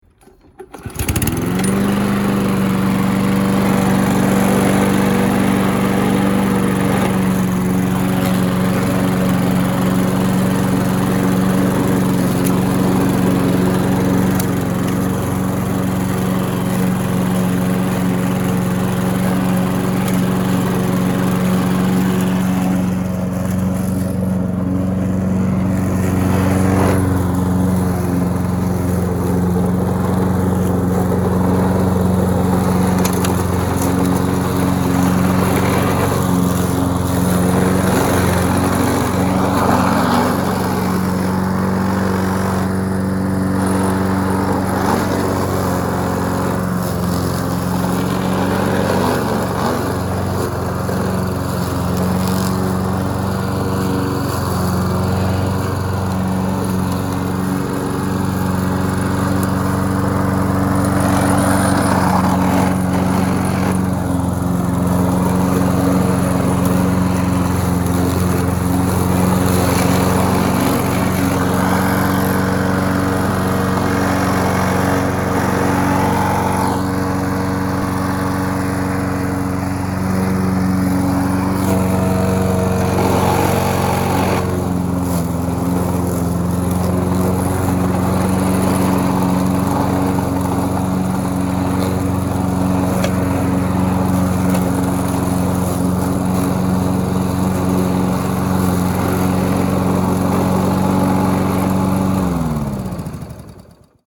Звуки газонокосилки
Дергаем, но безрезультатно